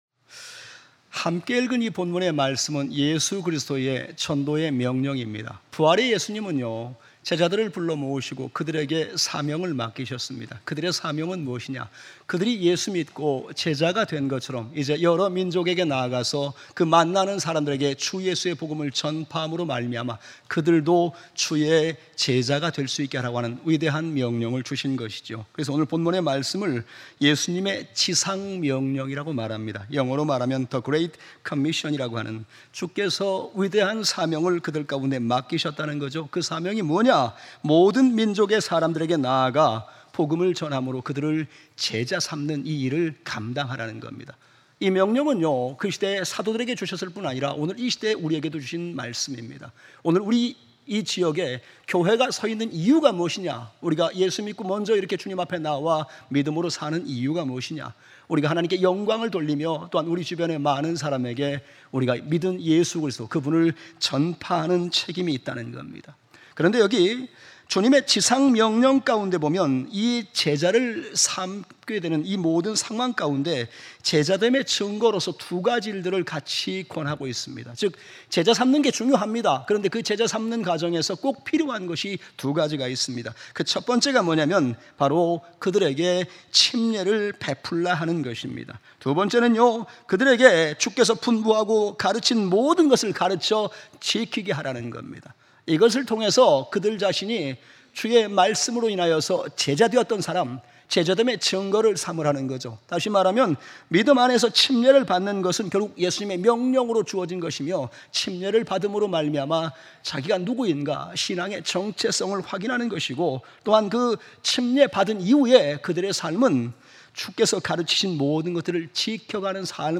아버지와 아들과 성령의 이름으로 침례를 베풀라 > 주일오전예배 | 경주제일침례교회